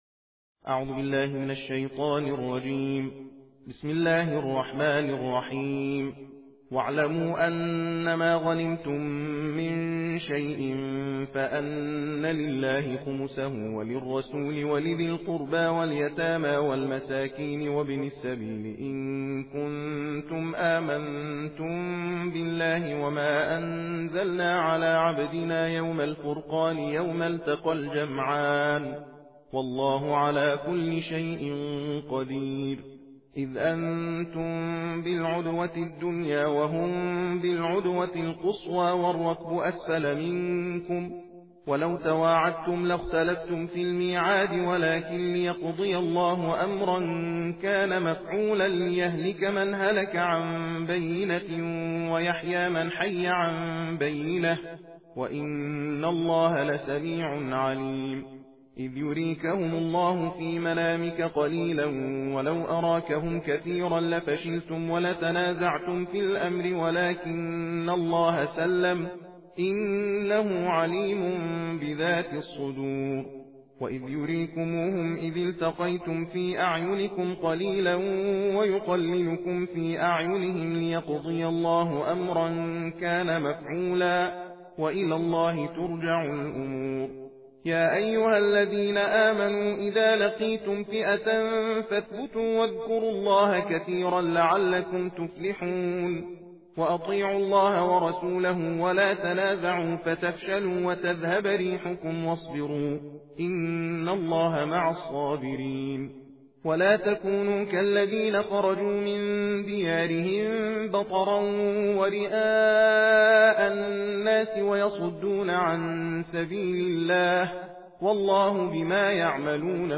صوت/ تندخوانی جزء دهم قرآن کریم